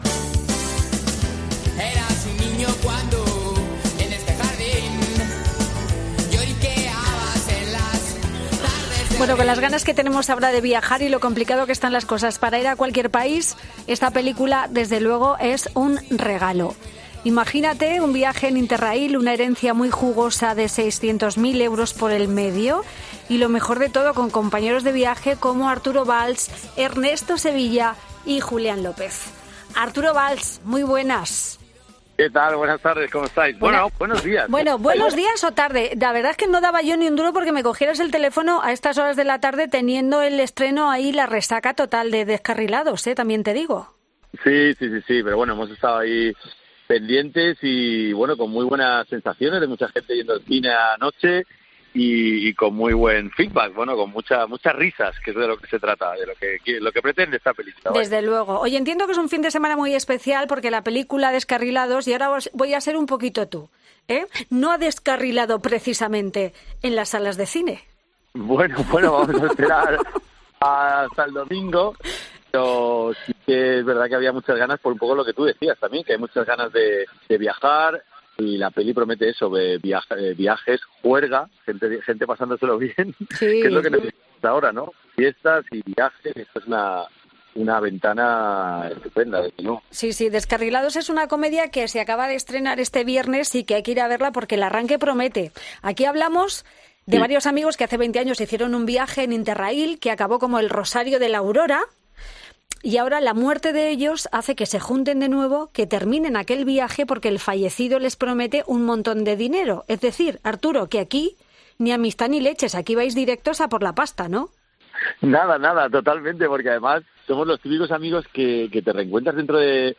El famoso presentador y actor pasa por Fin de Semana